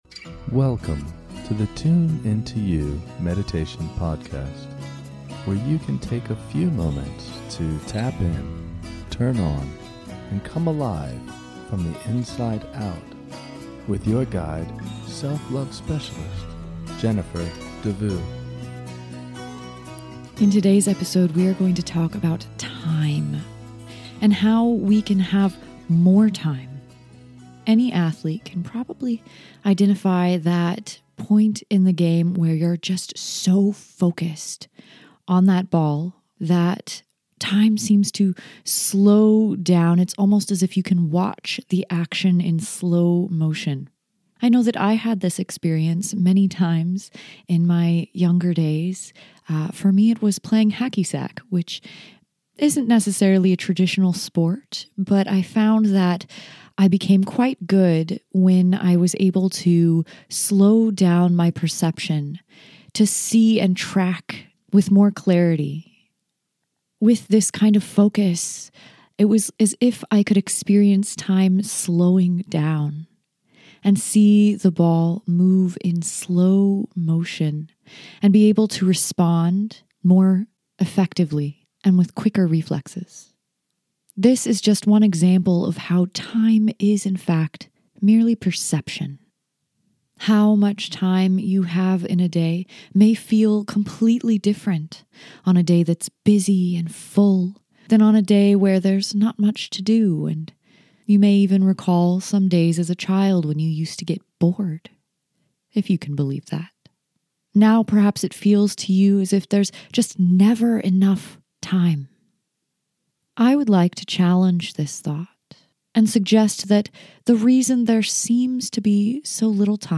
In this short guided meditation, we will explore the perception of time and learn a powerful practice for freeing up some of yours. So much precious time is wasted with busy, repetitive, and unproductive thoughts.